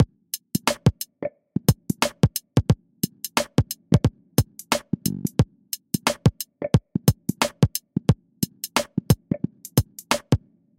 嘣嘣啪啪
Tag: 89 bpm Hip Hop Loops Drum Loops 1.82 MB wav Key : Unknown